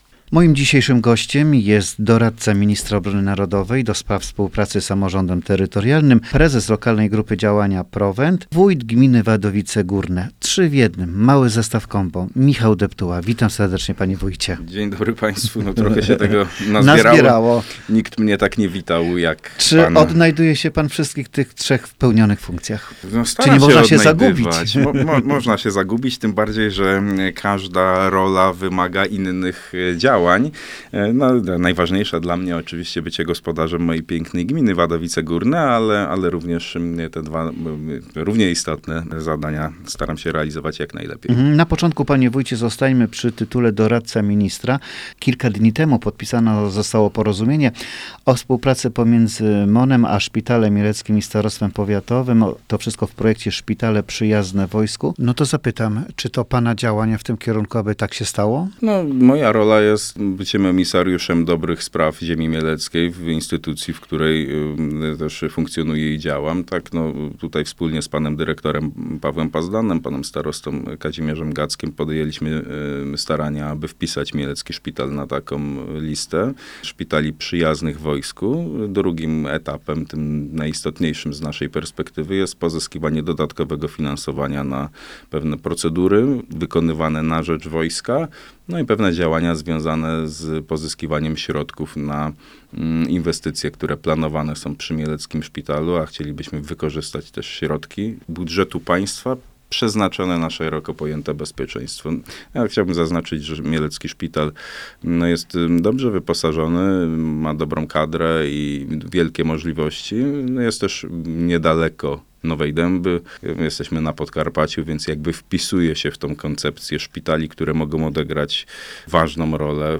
Rozmawiamy z Wójtem Gminy Wadowice Górne, Michałem Deptułą.
Rozmowa-Michal-Deptula-woj-Wadowice-Gorne.mp3